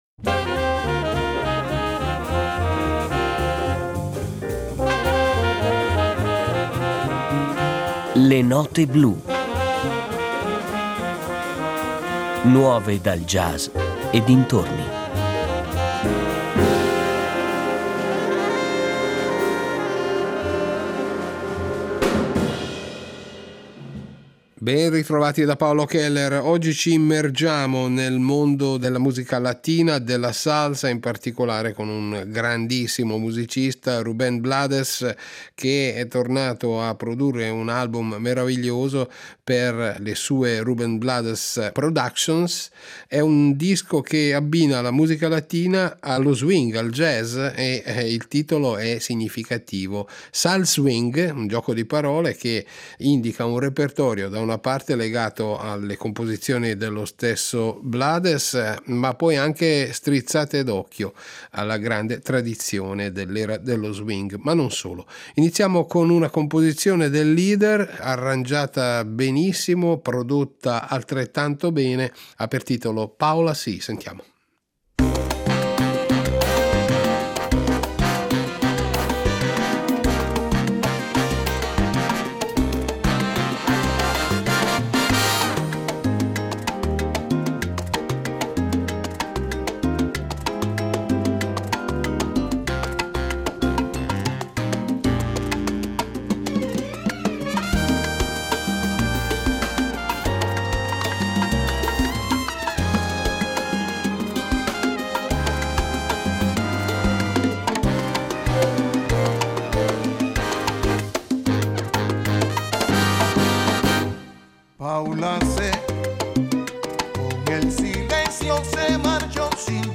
anche con sezione d’archi